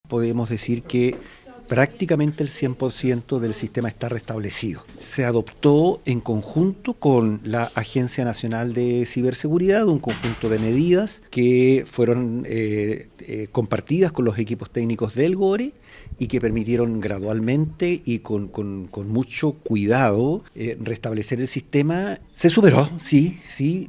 El propio gobernador de La Araucanía, René Saffirio, dijo que el ataque ya se superó.